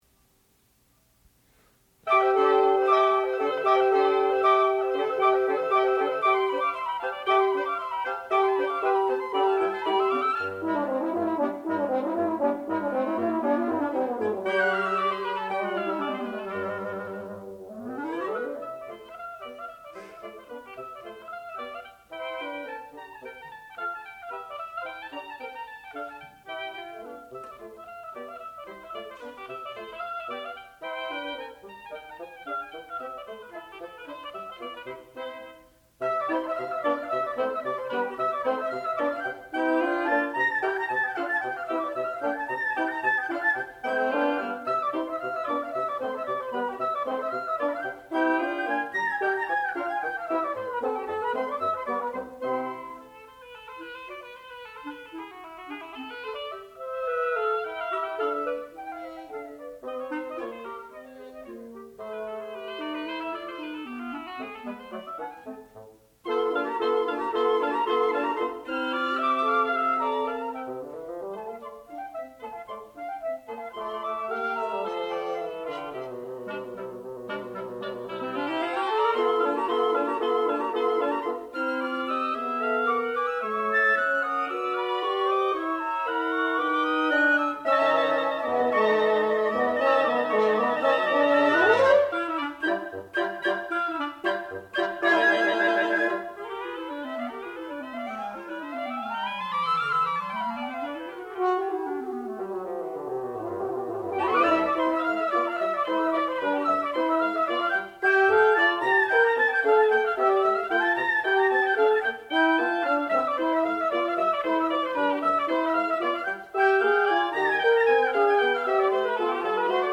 classical music
oboe
clarinet
flute